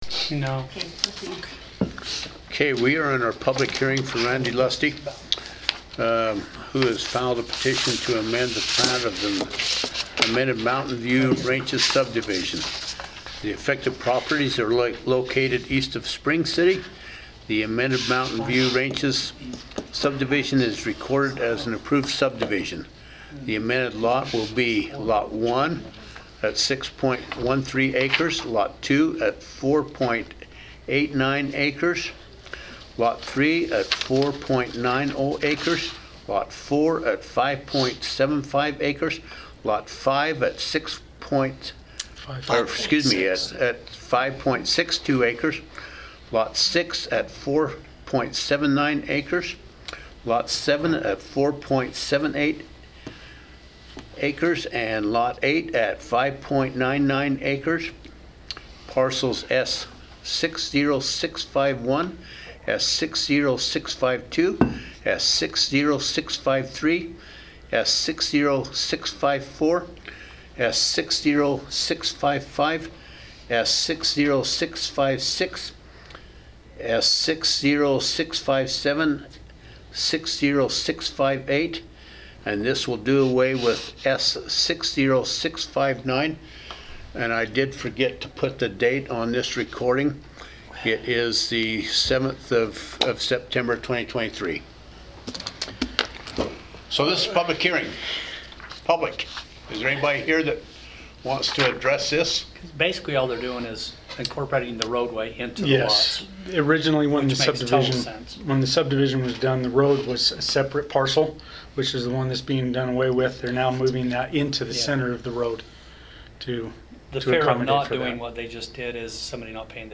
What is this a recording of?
Notice, Meeting, Hearing